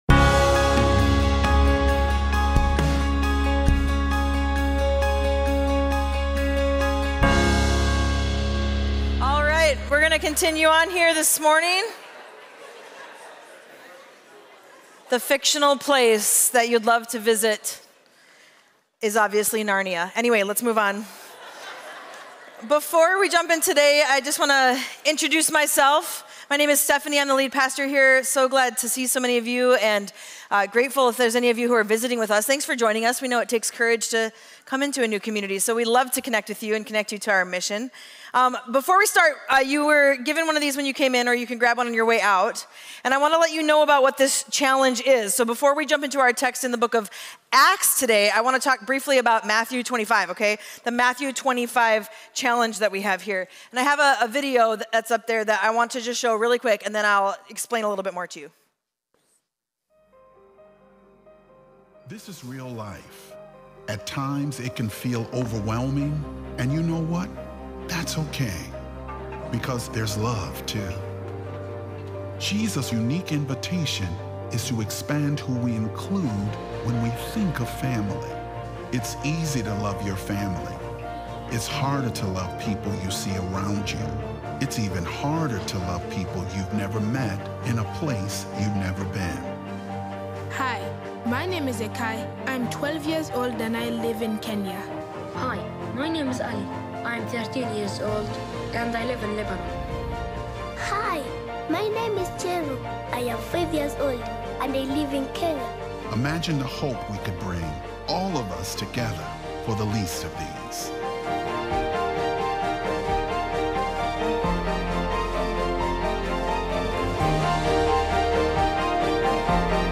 Mill City Church Sermons Authority in the Spirit: Empowered to Respond Aug 26 2024 | 00:37:55 1x 00:00 / 00:37:55 Subscribe Share RSS Feed Share Link Embed